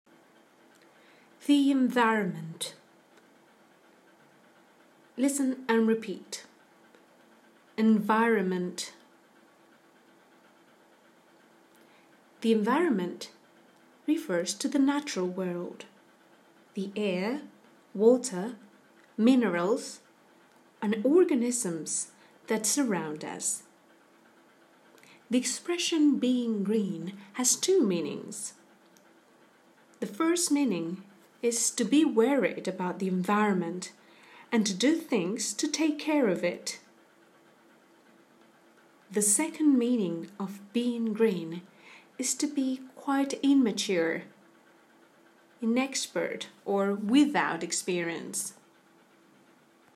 Click play to listen to the pronunciation of “environment” and to learn what this word and the expression “being green” mean.